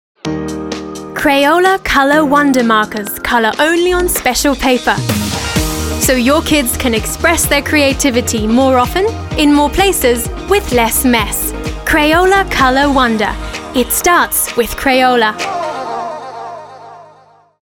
Radio Commercial EN
Upbeat promotion encouraging families to buy new generation markers. English with slight Australian accent.